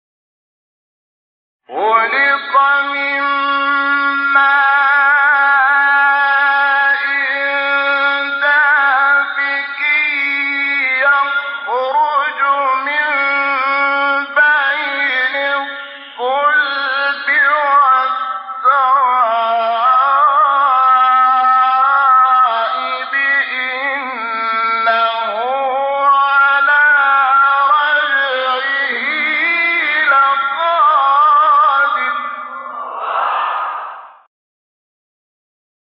سایت قرآن کلام نورانی - رست انورشحات (3).mp3
سایت-قرآن-کلام-نورانی-رست-انورشحات-3.mp3